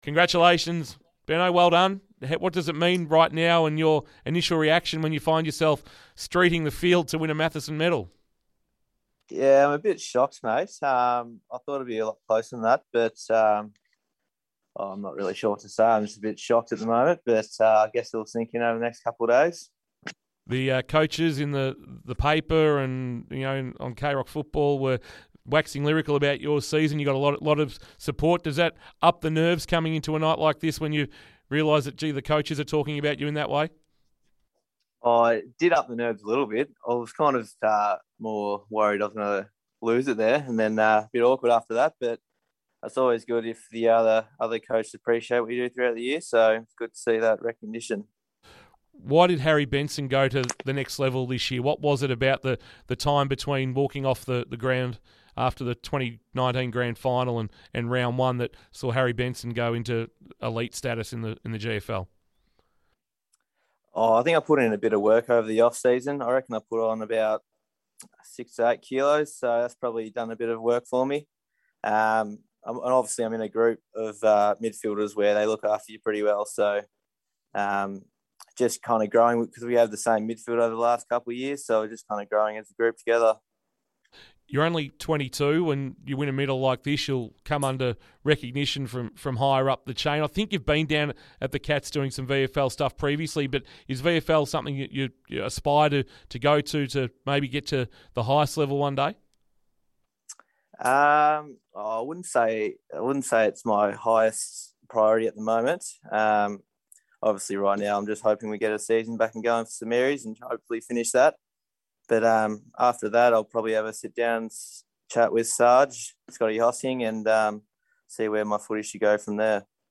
acceptance speech.